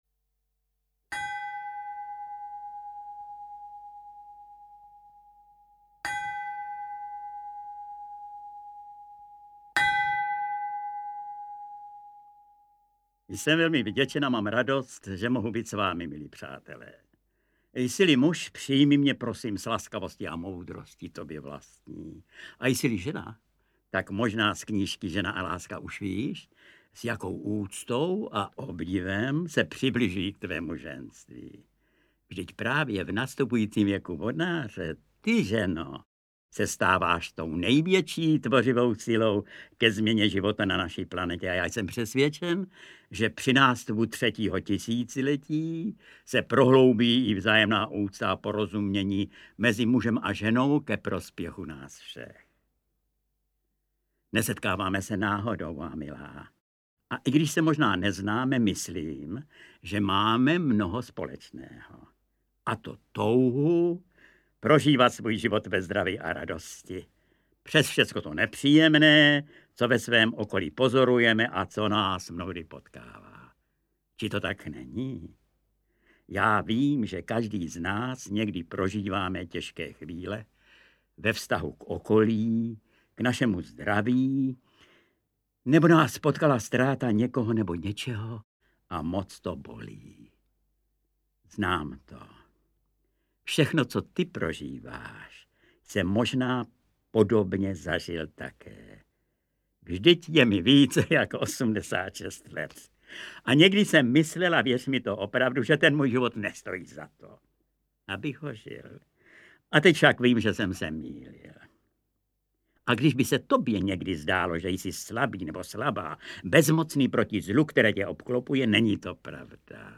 AudioKniha ke stažení, 2 x mp3, délka 54 min., velikost 124,5 MB, česky